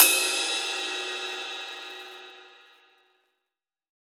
Dusty Cymbal 03.wav